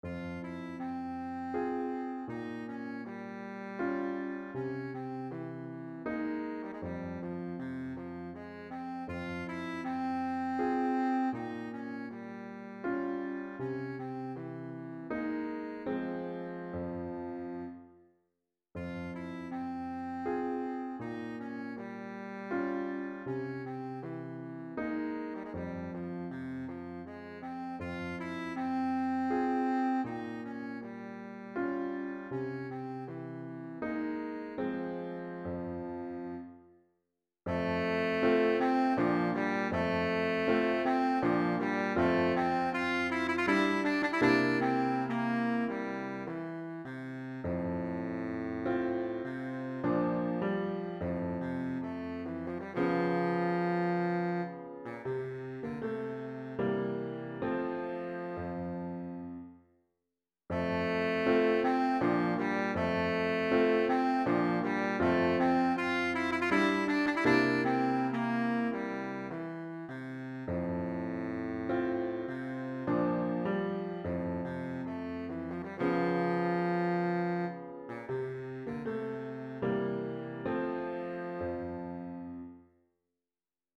Voicing: Baritone Saxophone and Piano